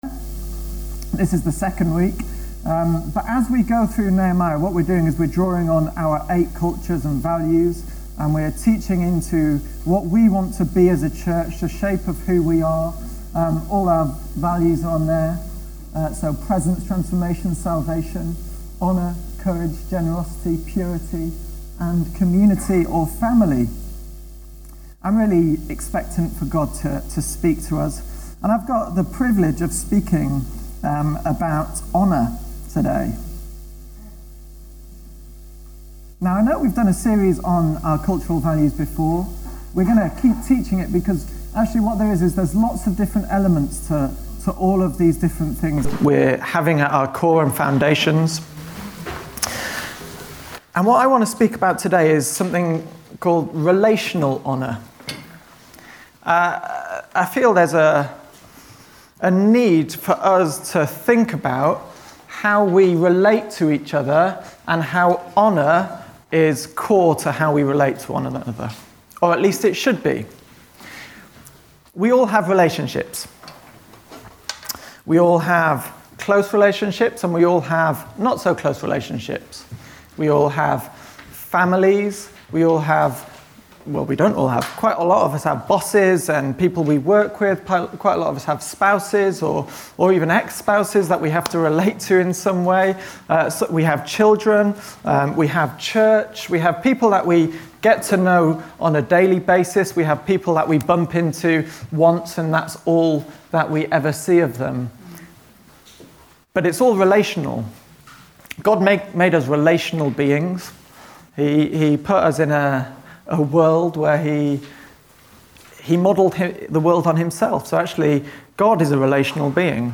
Download Nehemiah - Relational Honour | Sermons at Trinity Church